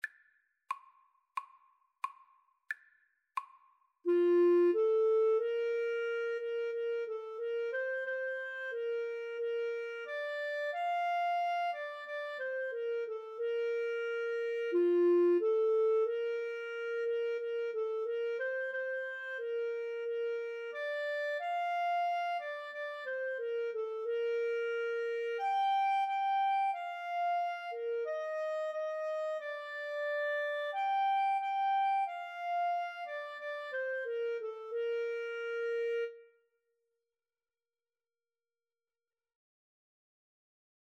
Bb major (Sounding Pitch) C major (Clarinet in Bb) (View more Bb major Music for Clarinet Duet )
Clarinet Duet  (View more Easy Clarinet Duet Music)